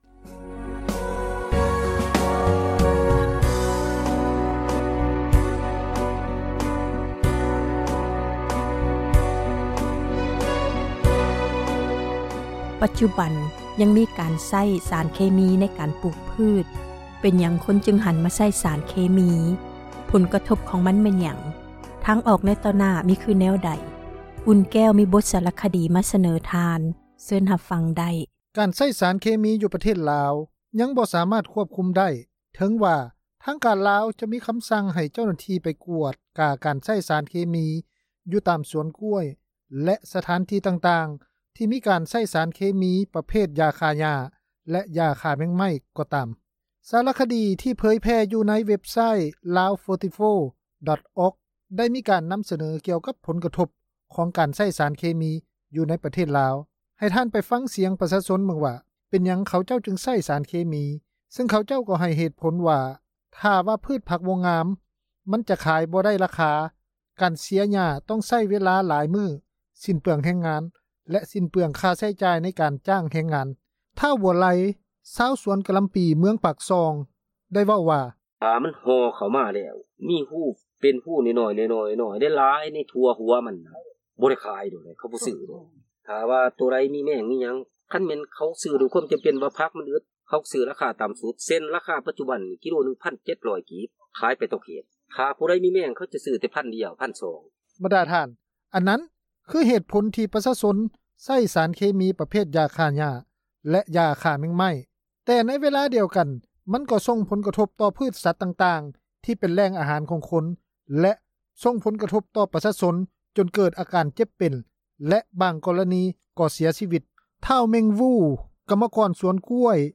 ຜົນຮ້າຍຈາກສານເຄມີ ປູກຝັງ — ຂ່າວລາວ ວິທຍຸເອເຊັຽເສຣີ ພາສາລາວ